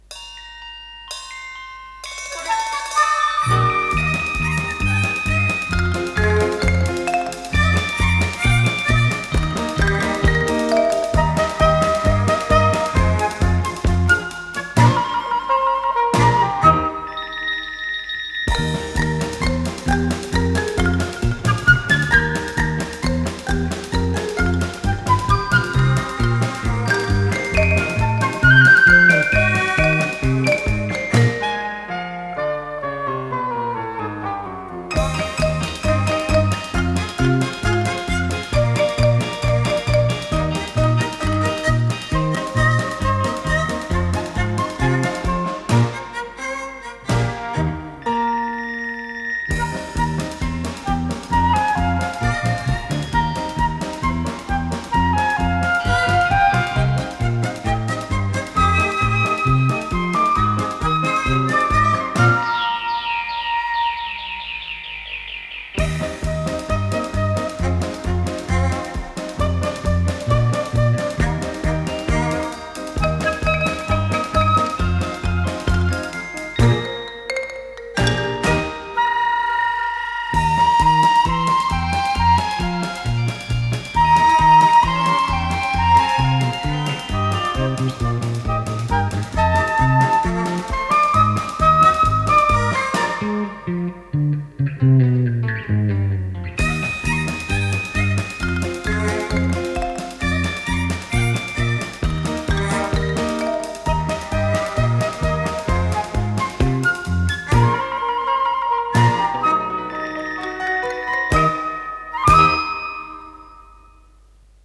ПодзаголовокЗаставка, ми бемоль мажор
ВариантДубль моно